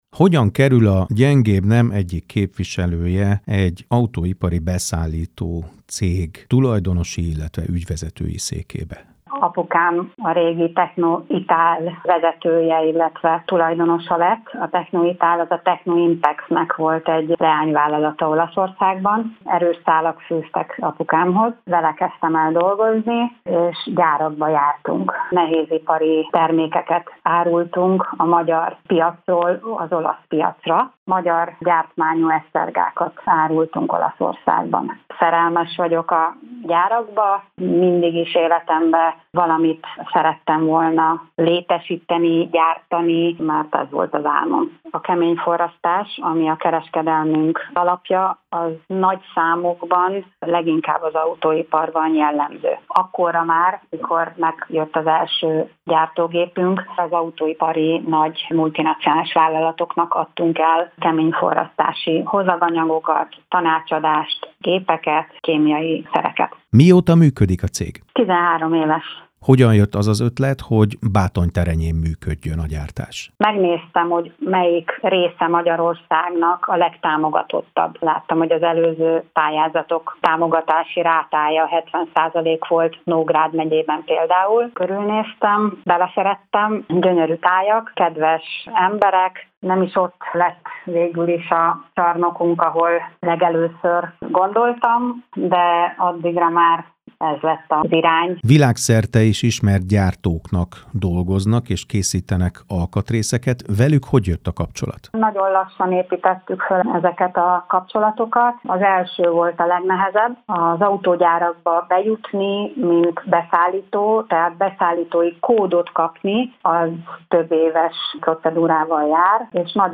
Reggeli Monitor című műsor